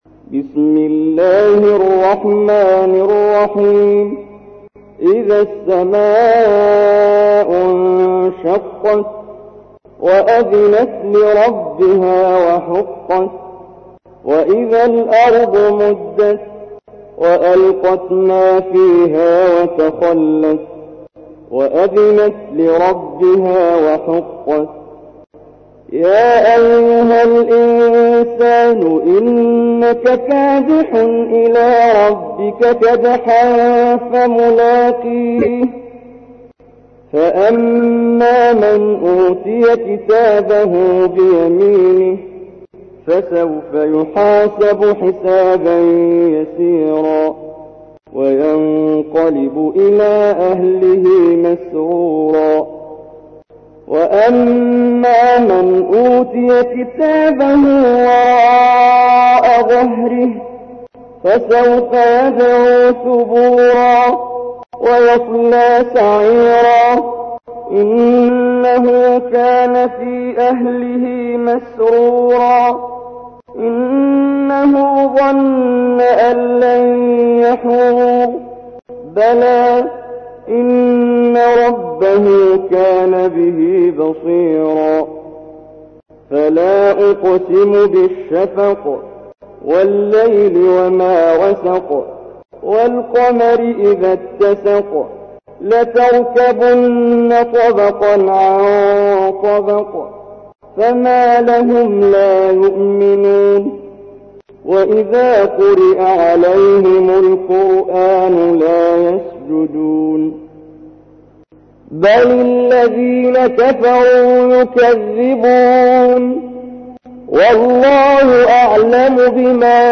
تحميل : 84. سورة الانشقاق / القارئ محمد جبريل / القرآن الكريم / موقع يا حسين